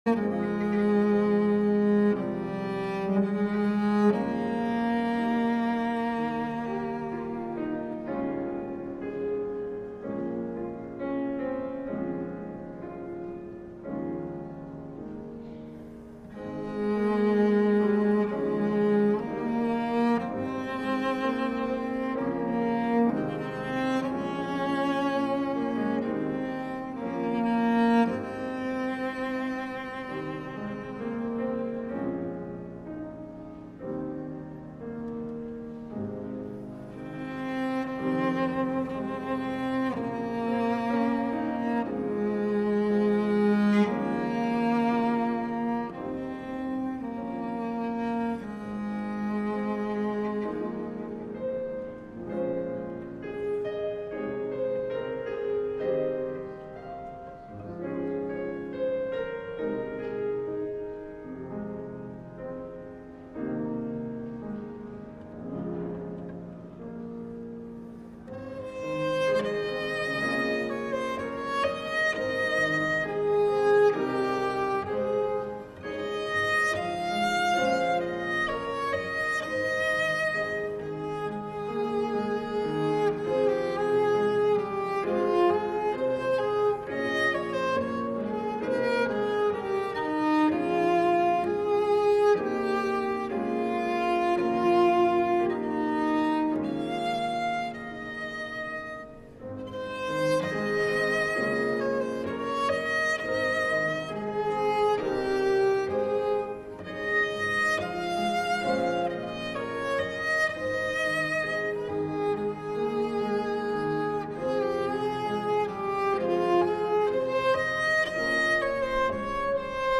LIVE Morning Service - Cross Words: Foreshadowings